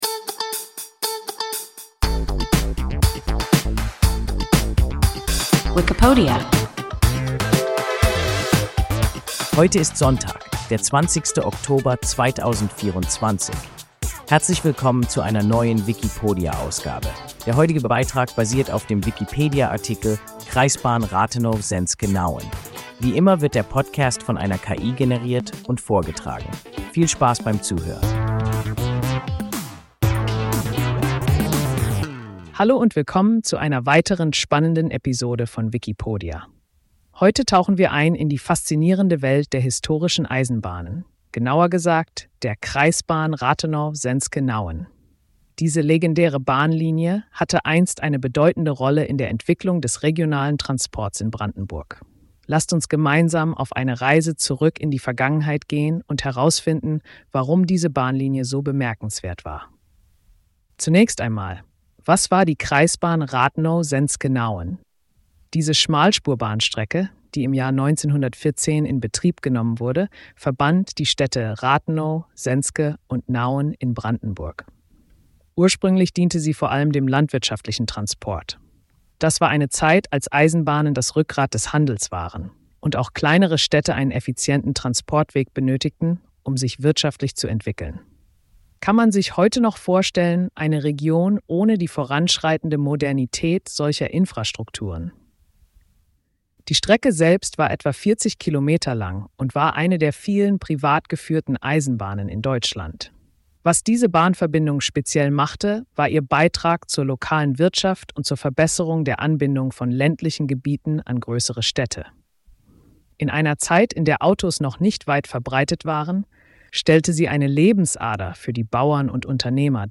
Kreisbahn Rathenow-Senzke-Nauen – WIKIPODIA – ein KI Podcast